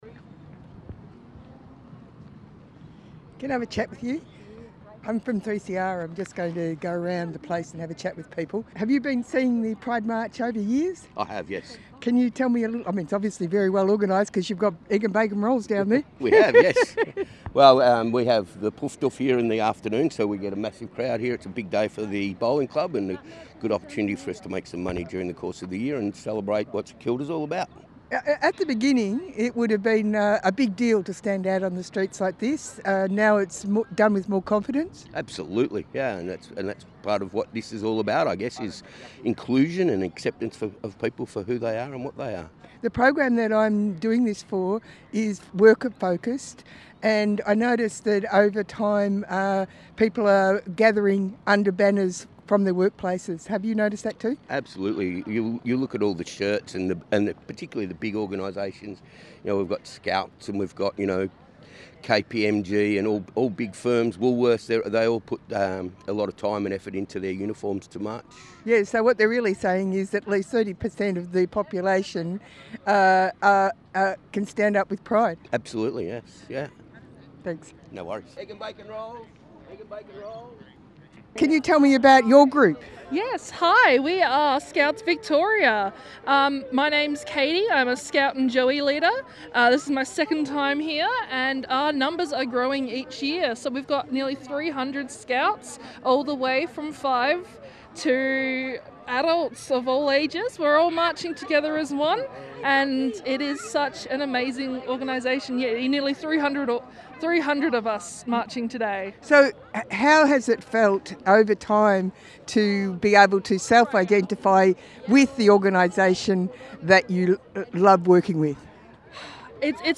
We follow with a word from people who gathered for the Pride March down Fitzroy St, St Kilda on the 2 of February